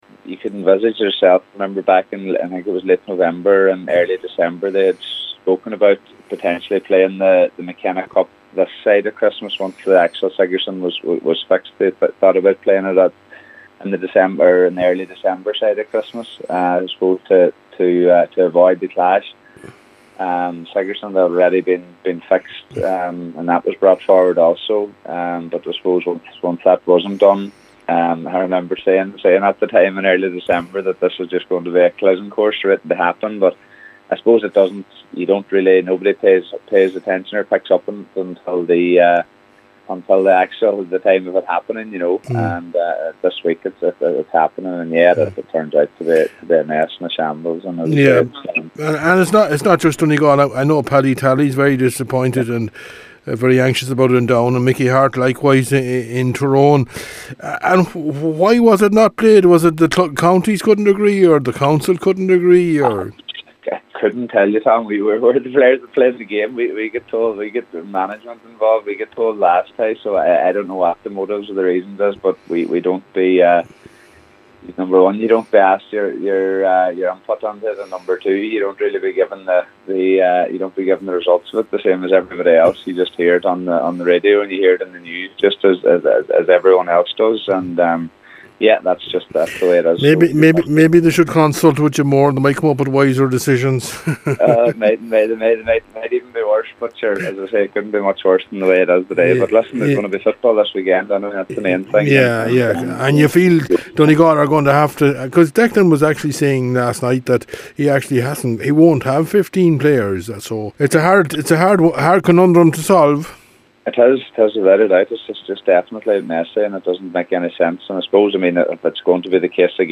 Captain Michael Murphy who hasn’t played this year so far this campaign says the situation is a mess…